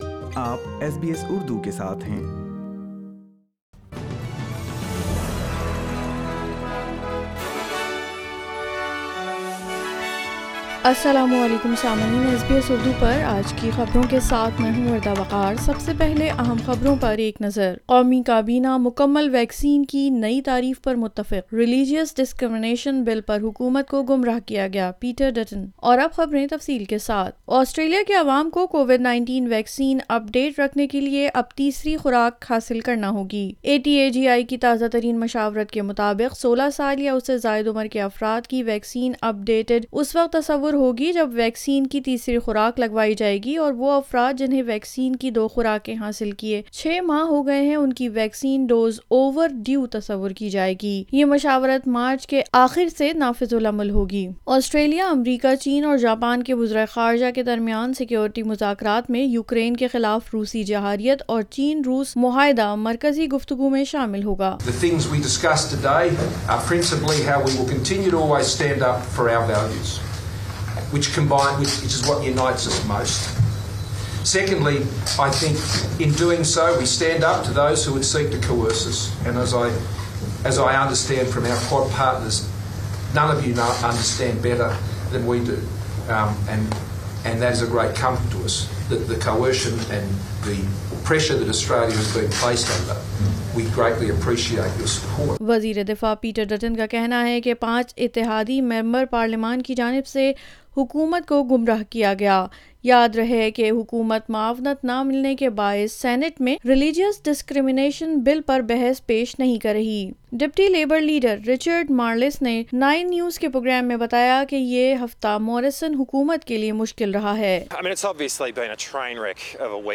SBS Urdu News 11 February 2022